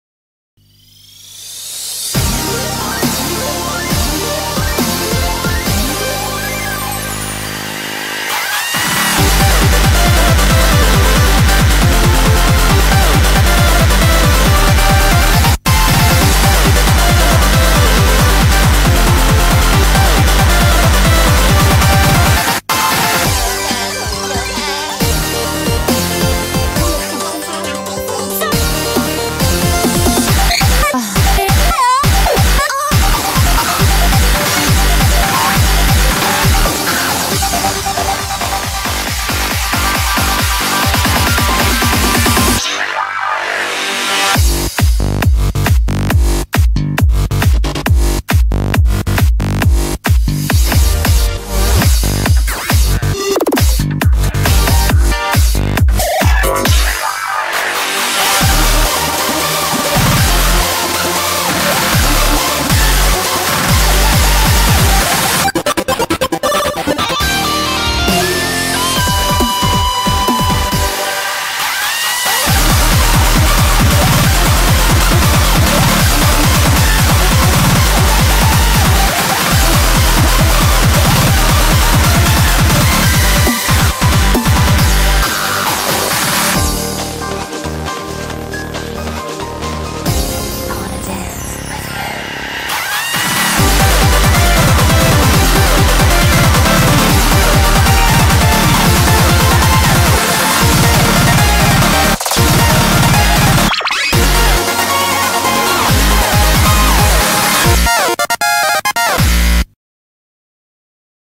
BPM68-273
Audio QualityPerfect (Low Quality)